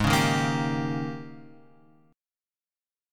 G#M7sus2sus4 chord {4 4 1 3 x 3} chord